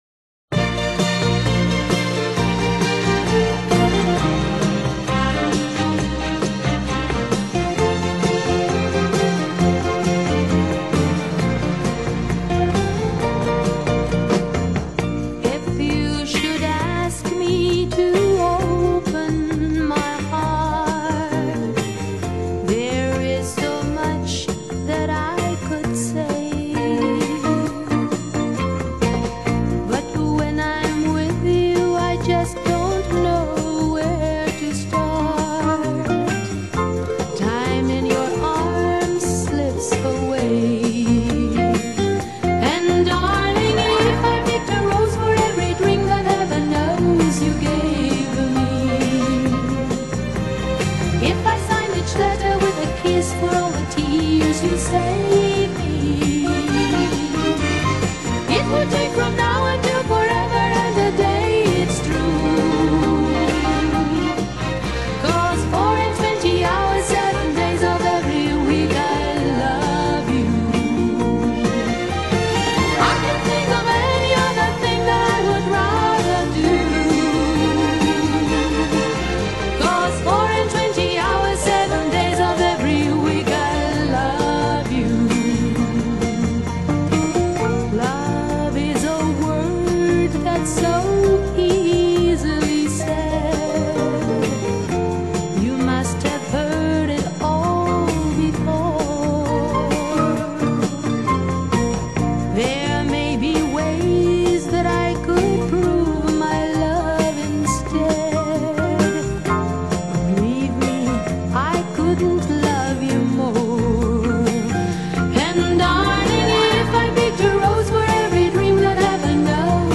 Genre: Pop, Folk,Opera, Vocal, New Age